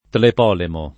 [ tlep 0 lemo ]